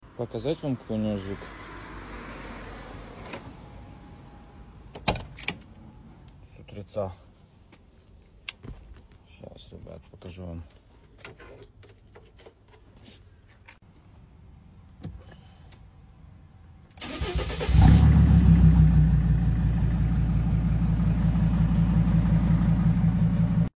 Вот как звучит Спринтер 2.7#sprintervan